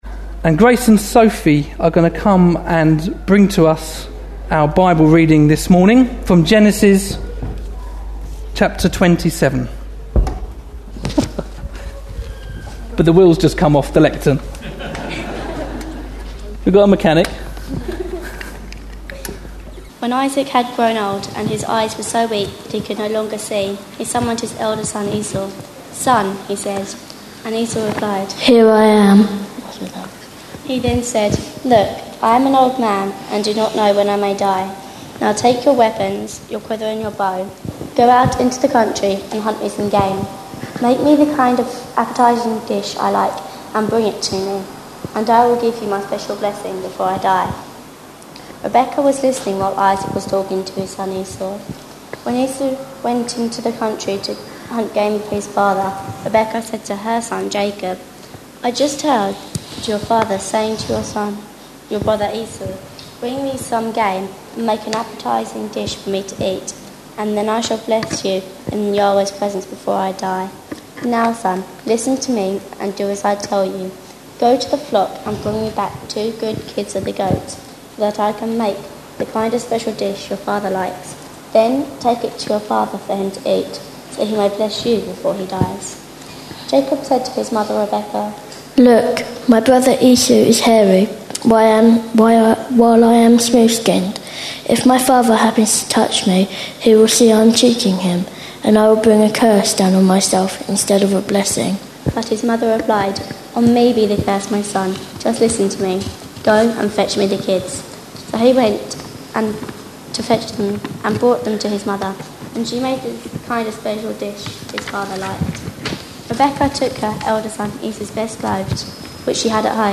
A sermon preached on 8th August, 2010, as part of our Old Testament Couples series.